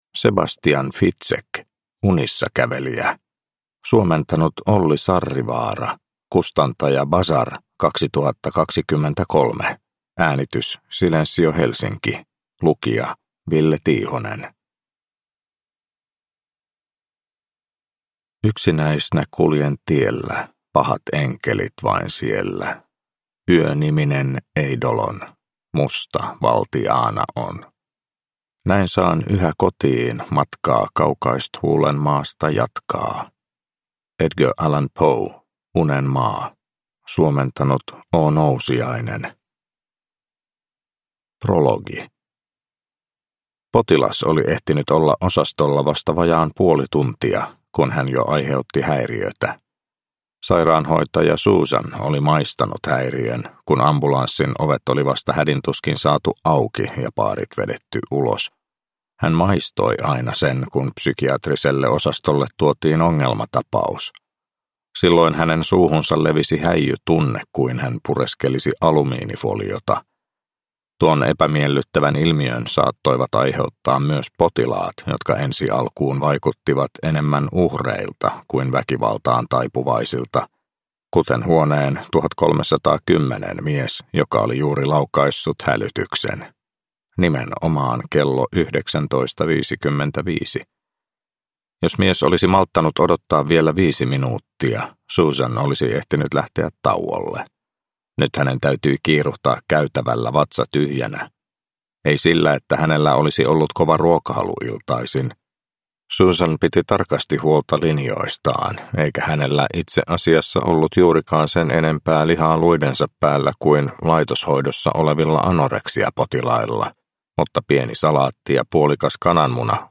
Unissakävelijä – Ljudbok – Laddas ner